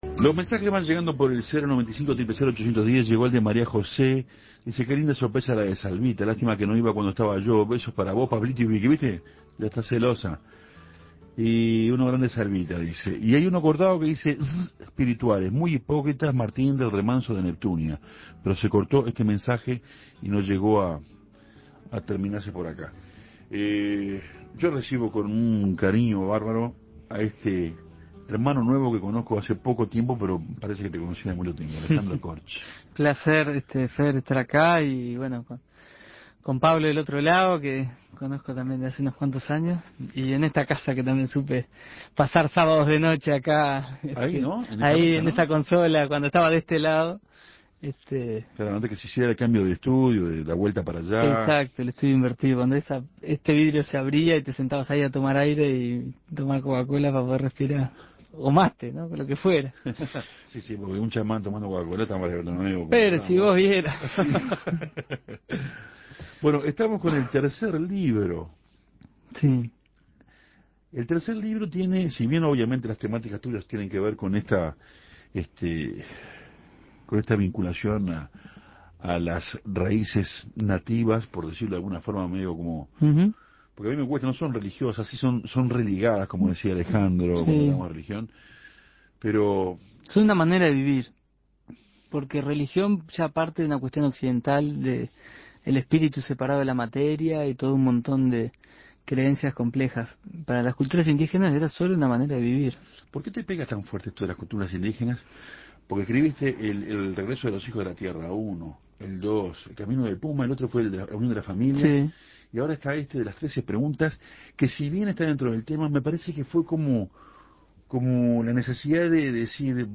fue entrevistado en Café Torrado